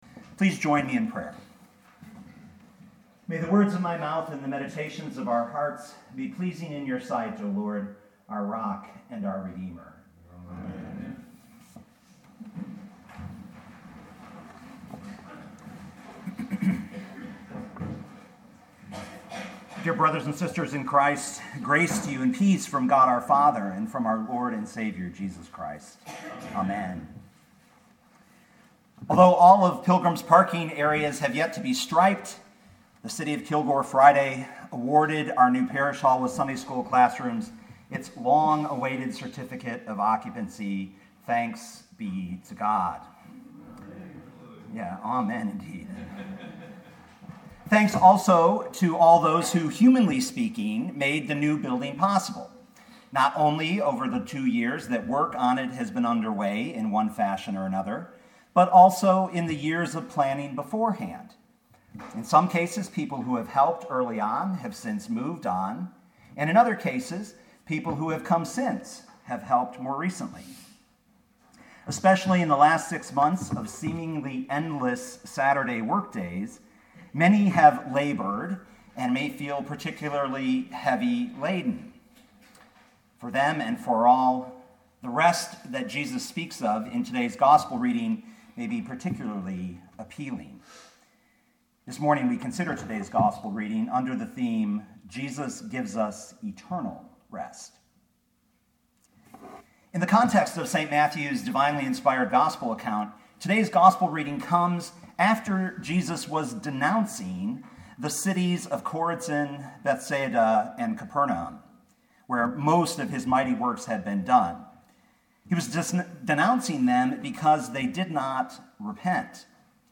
2017 Matthew 11:25-30 Listen to the sermon with the player below